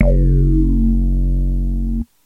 Korg Mono Poly Phase Bass " Korg Mono Poly Phase Bass F3（12 DODDED Bass55127
标签： FSharp3 MIDI音符-55 Korg的单 - 聚 合成器 单注 多重采样
声道立体声